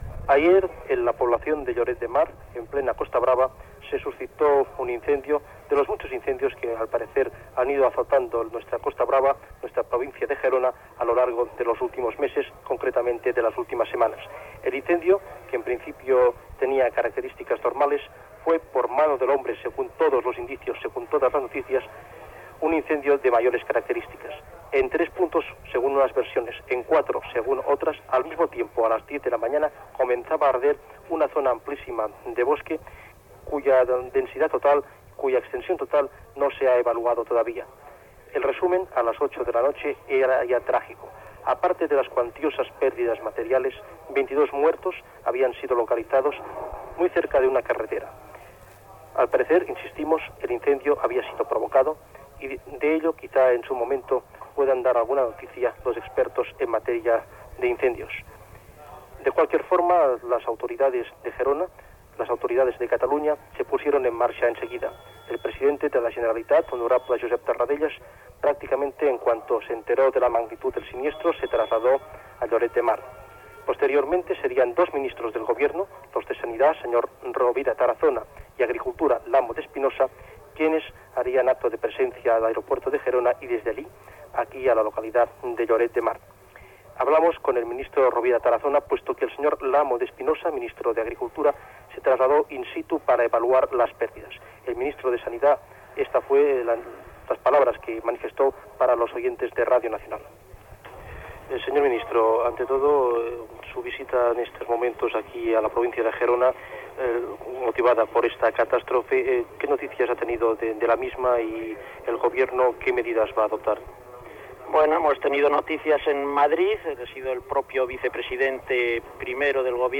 Informació de l'incendi forestal que el dia anterior s'havia produït a Lloret de Mar, causant 22 morts. Entrevista informativa al Ministre de Sanitat, Juan Rovira Tarazona
Informatiu